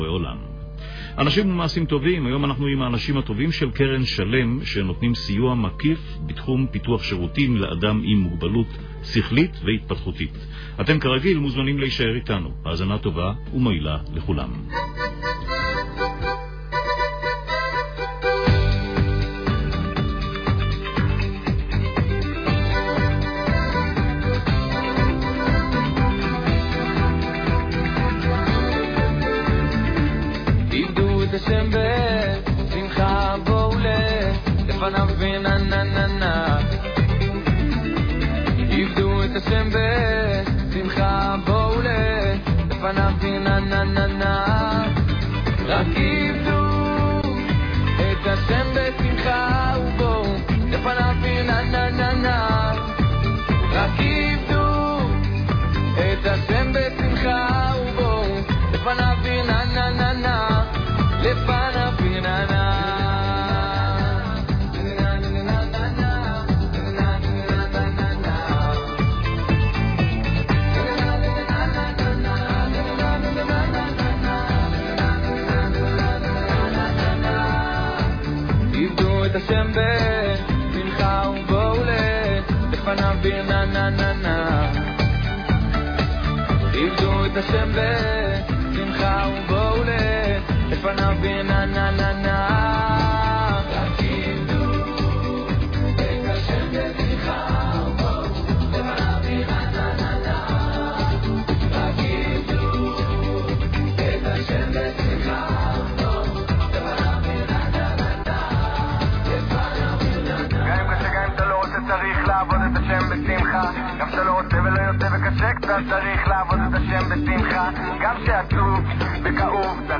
ברשת מורשת